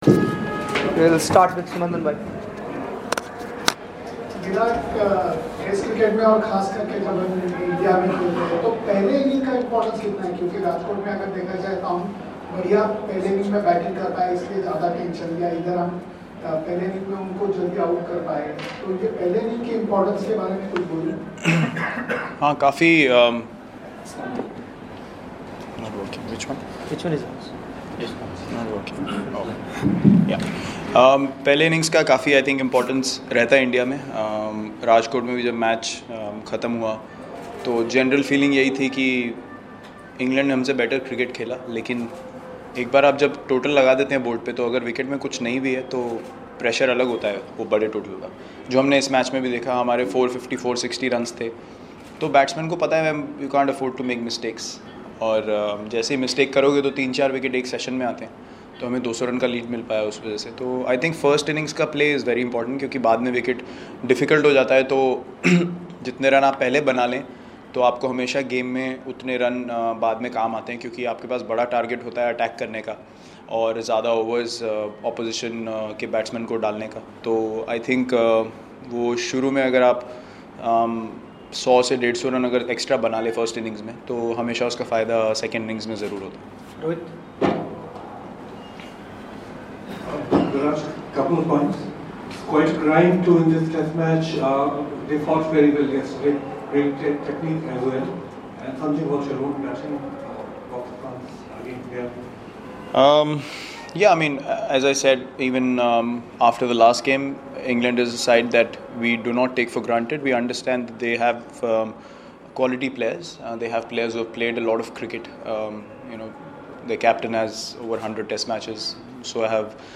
LISTEN: Skipper Virat Kohli speaking after India's big win in Vizag